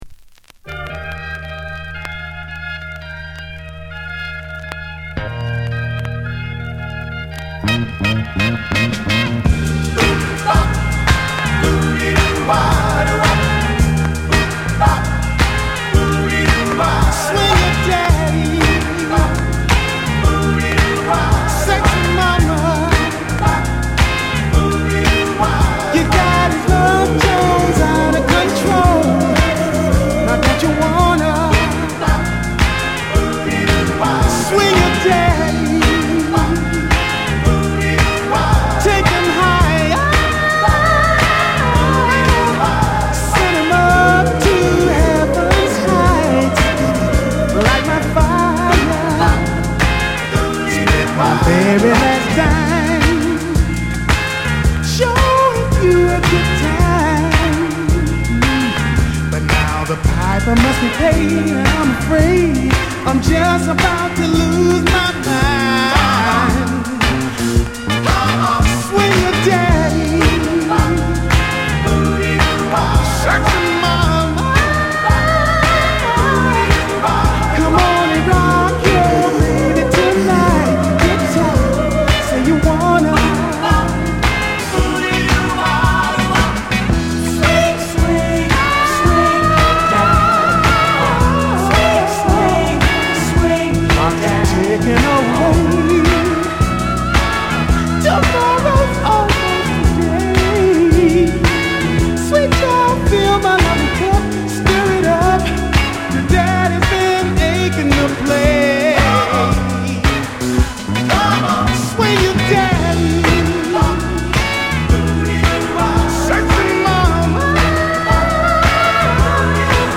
擦れによるチリノイズ有り